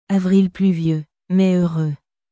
avril pluvieux, mai heureuxアヴリル プリュヴュ ンメ ウールー